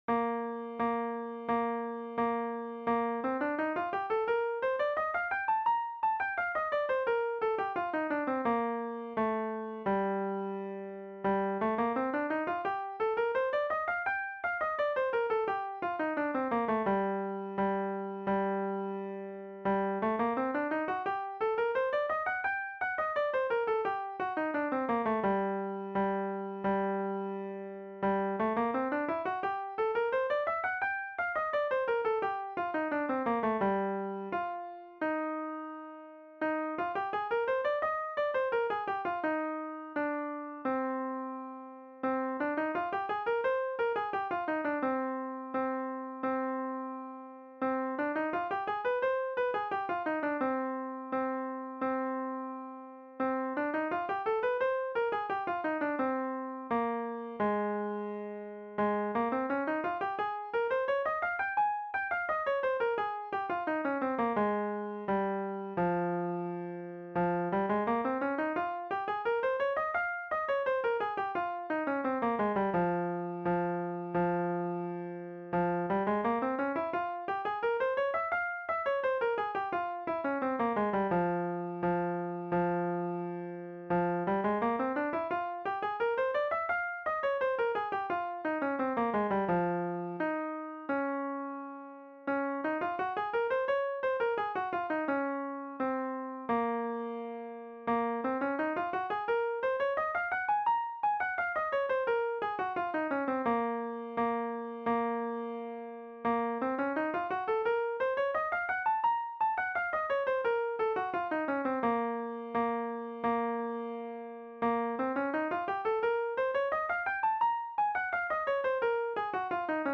Trumpet Scale Routine (play-along for B-flat trumpet)
Trumpet-Scale-Routine-Audio.mp3